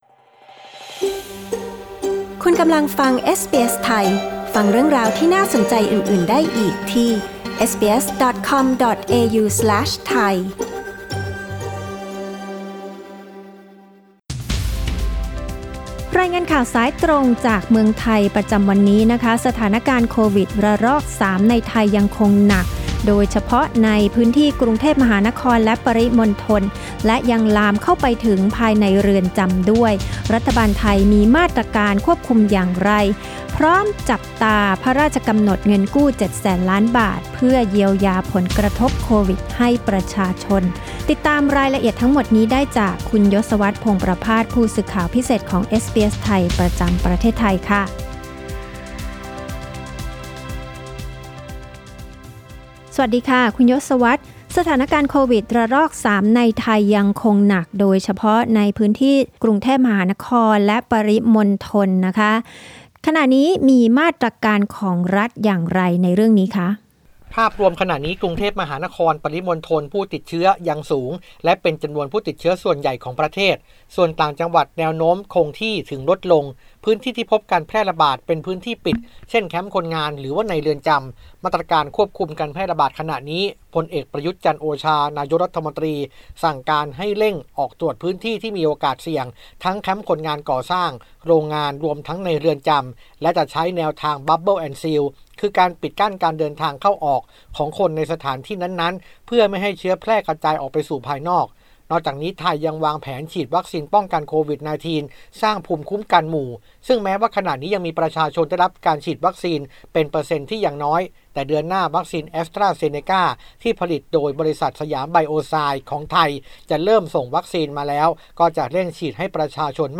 รายงานสายตรงจากเมืองไทย โดยเอสบีเอส ไทย Source: Pixabay